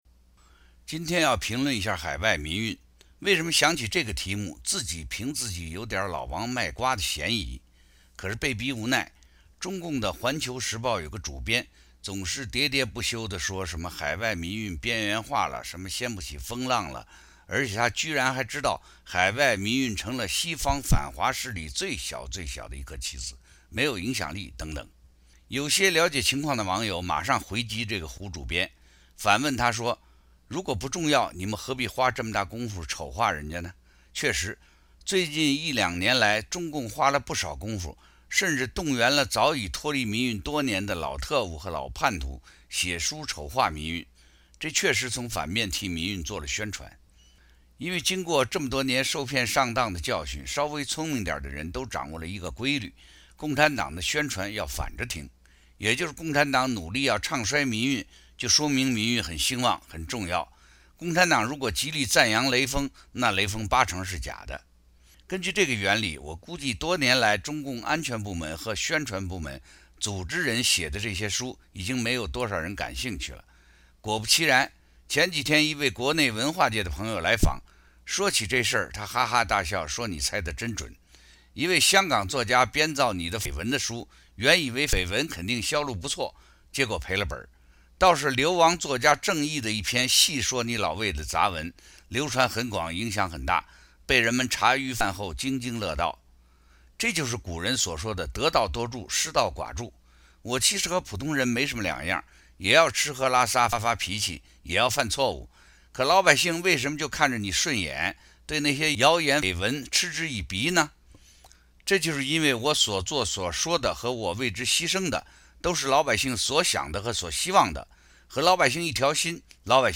（撰写并录音于2013年8月15日。自由亚洲电台播出。）